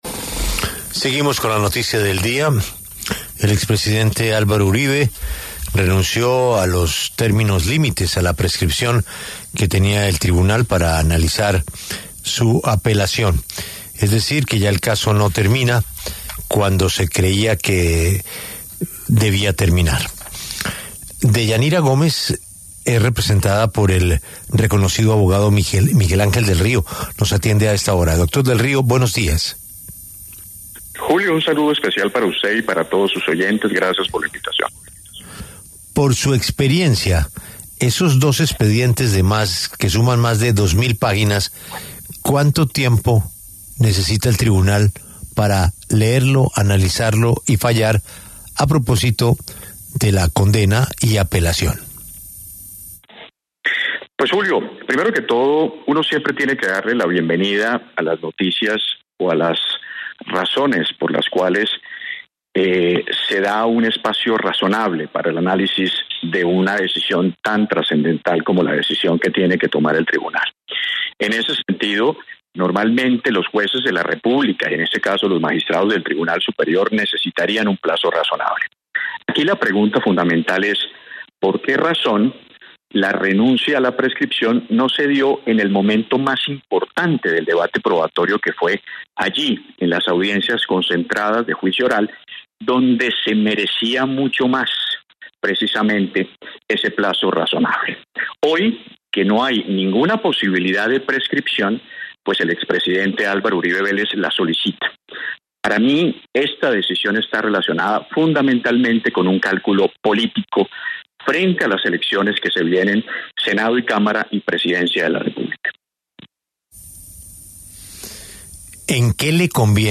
Debate: ¿Renuncia de Álvaro Uribe a prescripción de su caso es un cálculo político?